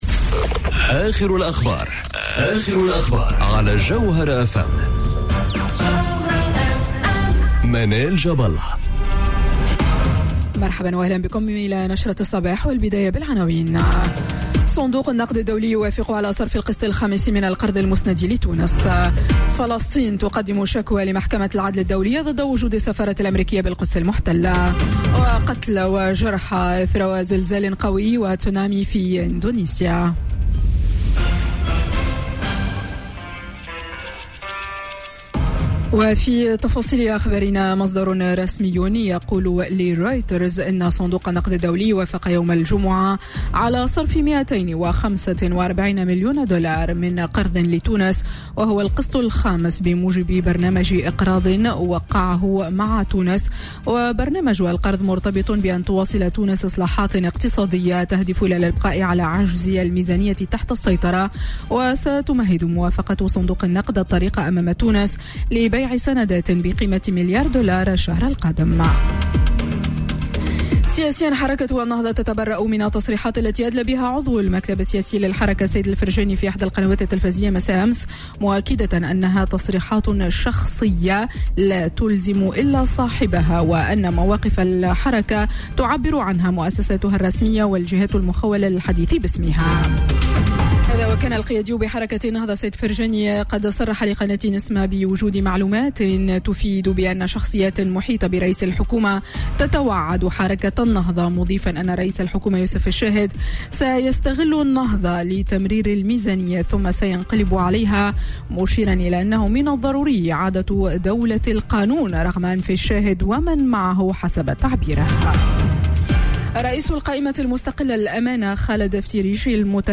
نشرة أخبار السابعة صباحا ليوم السبت 29 سبتمبر 2018